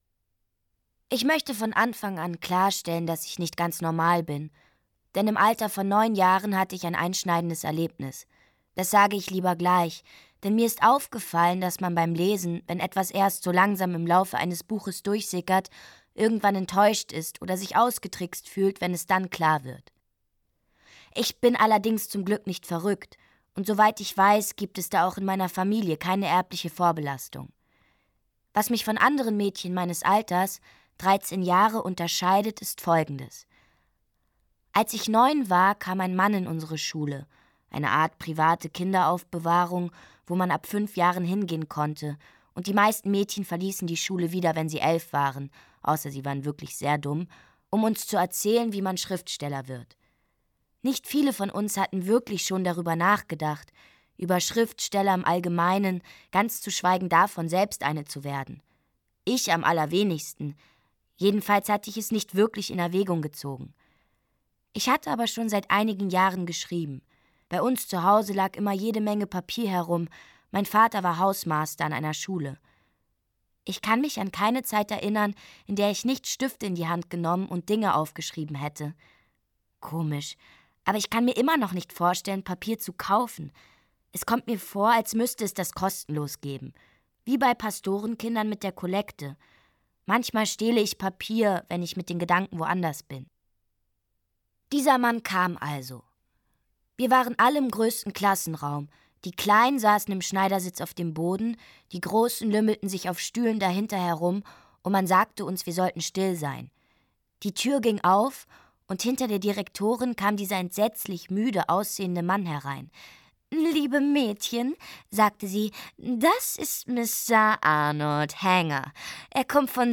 Schlagworte 1940er Jahre • Badeort • Coming of Age • England • Fliegerangriff • Großbritannien • Großbritannien • Hörbuch Literatur • Hörbuch Roman • Hörbuch ungekürzt • Hörbuch Literatur • Hörbuch Roman • Hörbuch ungekürzt • Jane Gardem • Mädchen • Mädchen • Old Filth • Rebellion • Schriftstellerin • Shakespeare • Unangepasstheit